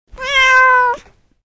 cat_1.mp3